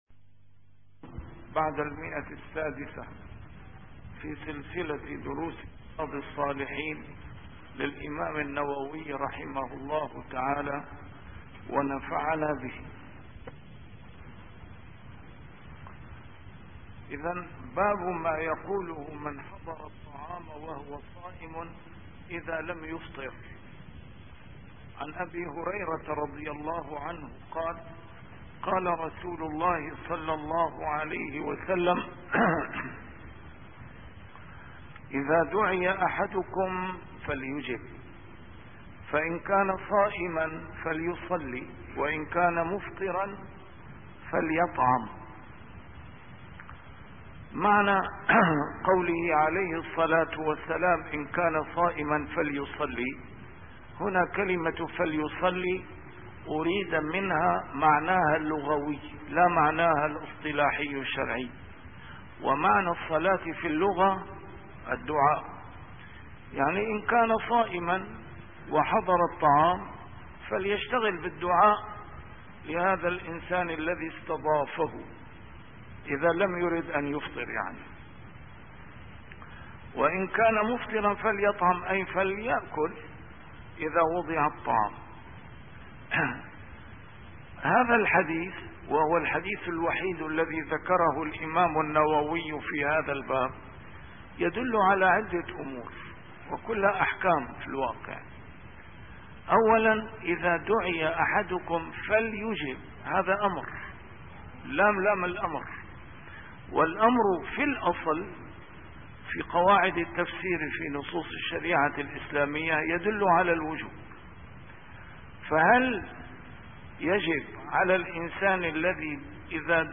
A MARTYR SCHOLAR: IMAM MUHAMMAD SAEED RAMADAN AL-BOUTI - الدروس العلمية - شرح كتاب رياض الصالحين - 644- شرح رياض الصالحين: من حضر الطعام وهو صائم